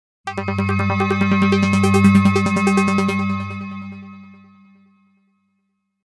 Hot News Sting
hot-news-sting.mp3